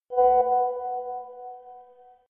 incoming-message-online-whatsapp.mp3